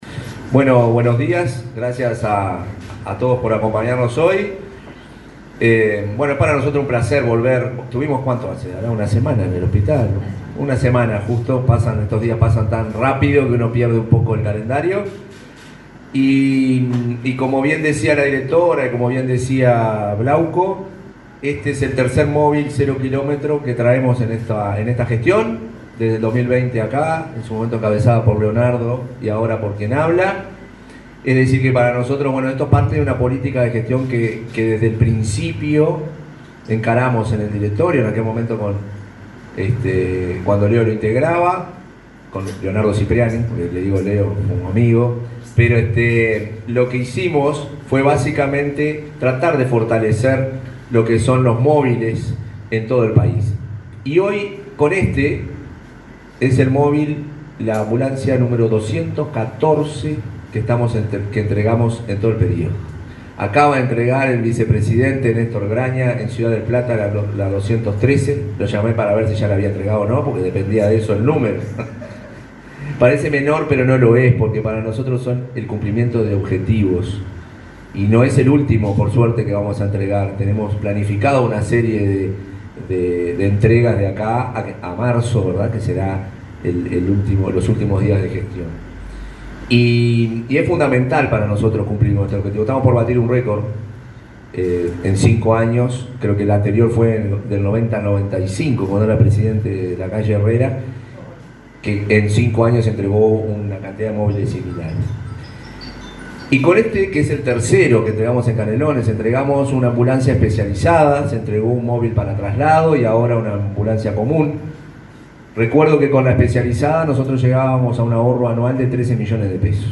Palabras del presidente de ASSE, Marcelo Sosa
Palabras del presidente de ASSE, Marcelo Sosa 22/11/2024 Compartir Facebook X Copiar enlace WhatsApp LinkedIn Este viernes 22, el presidente de la Administración de los Servicios de Salud del Estado (ASSE), Marcelo Sosa, encabezó el acto de entrega de una ambulancia en el hospital de Canelones.